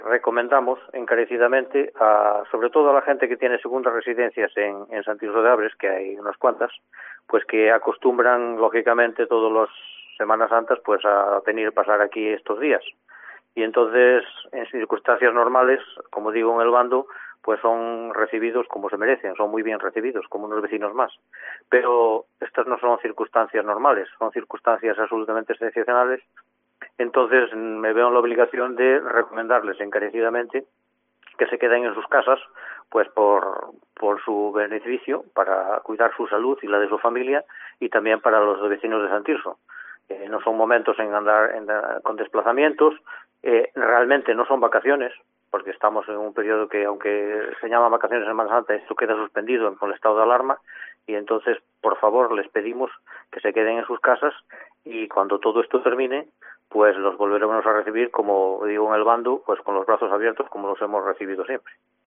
Declaraciones de CLEMENTE MARTÍNEZ, alcalde de San Tirso de Abres